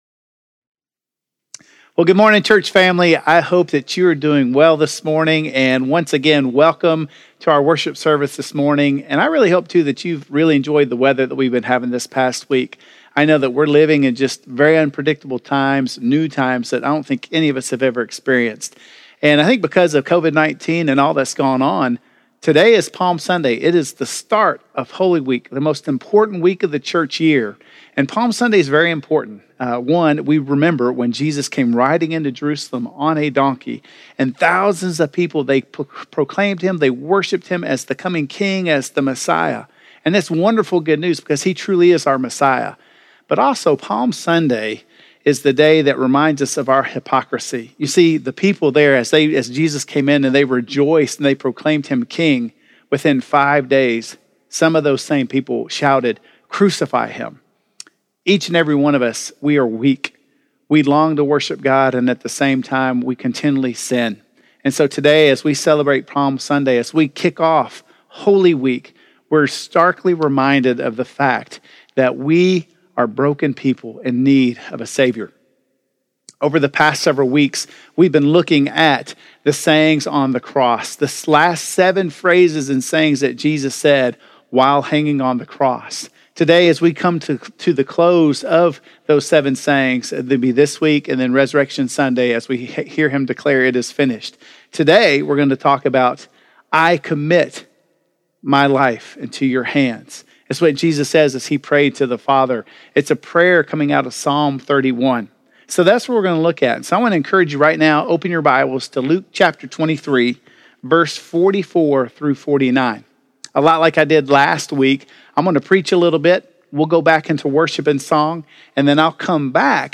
Into Your Hands I Commit My Spirit - Sermon - Woodbine
Woodbine_Sermon_April_5.mp3